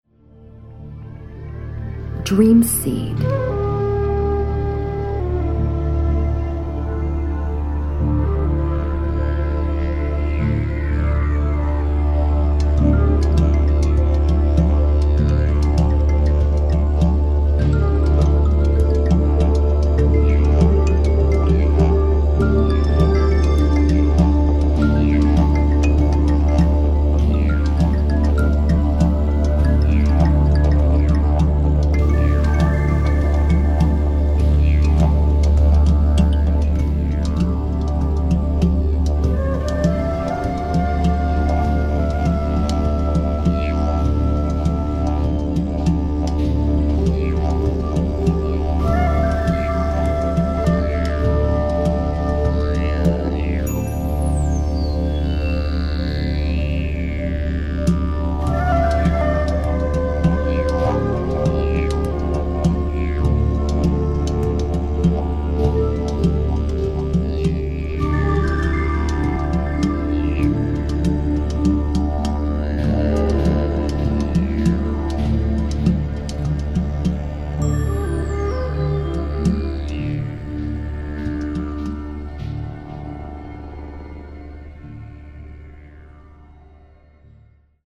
za použití nízkých kmitavých tónů a bambusové píšťaly
Relaxace, Meditace, Relaxační a Meditační hudba
Verbální vedení: Neverbální